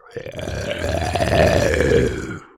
spawners_mobs_mummy_neutral.3.ogg